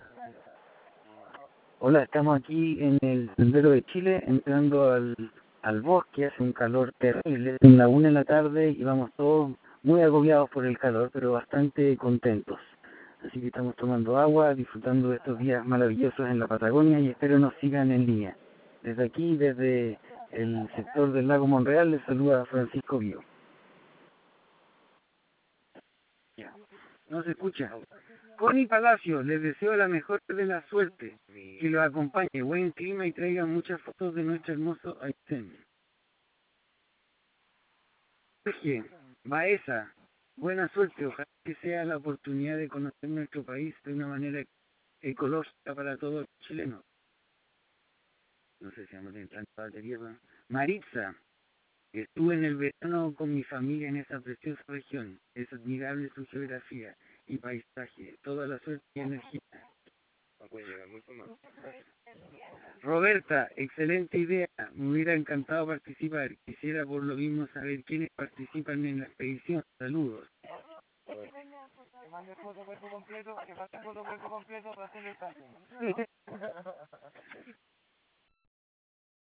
Dispatch created from email